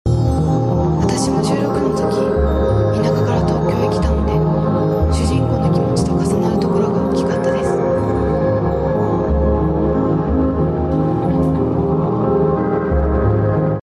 AI chalk mukbang!!